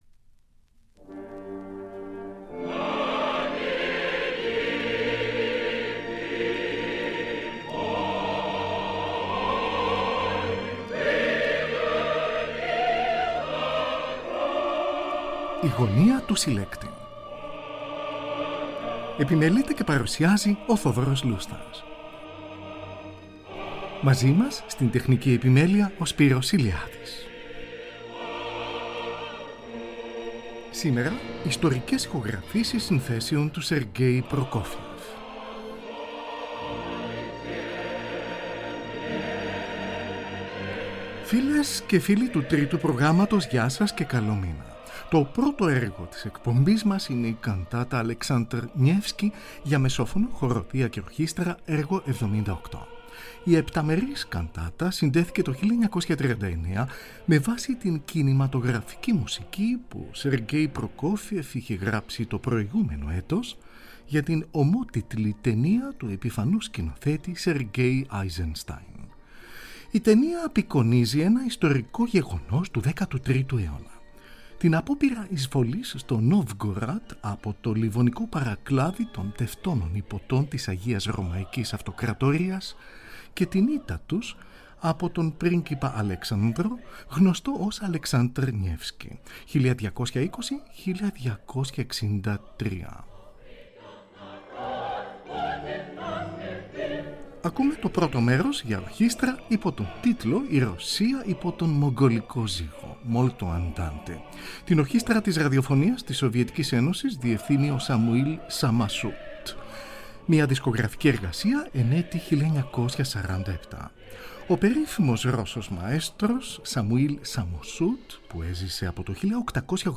Ιστορικές Ηχογραφήσεις
για μεσόφωνο , χορωδία και ορχήστρα
mezzo-soprano